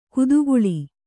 ♪ kuduguḷi